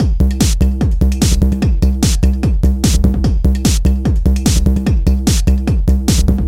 高能量舞蹈2
Tag: 148 bpm Techno Loops Drum Loops 1.09 MB wav Key : Unknown